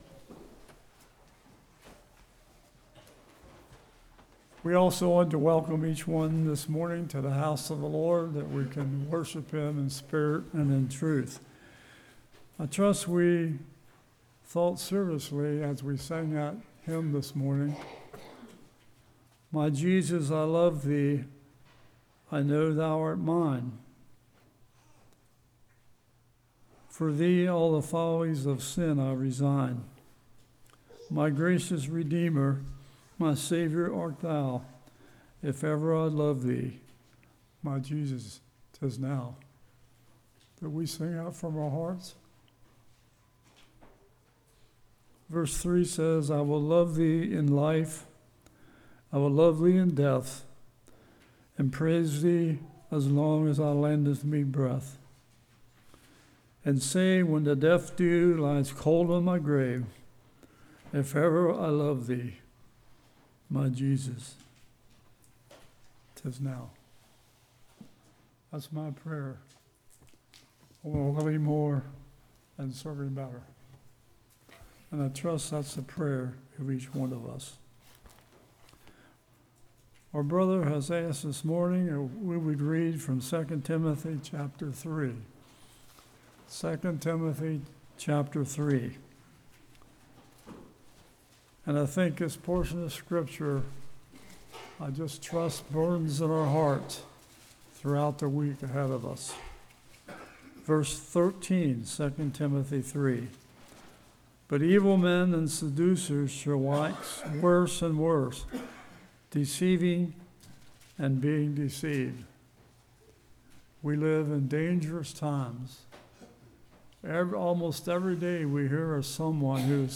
2 Timothy 3:13-17 Service Type: Morning Judge Truth By God’s Word God’s Word Makes us Perfect Is It Result Oriented or Scripture Oriented?